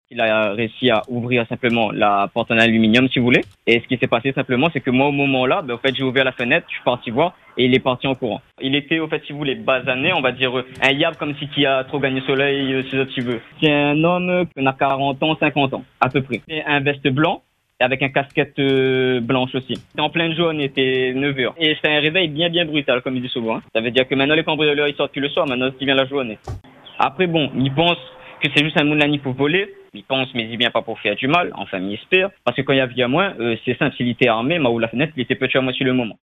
Encore sous le choc, l’habitant témoigne de ce réveil brutal, d’autant plus inquiétant que les faits se sont produits alors qu’il était présent chez lui.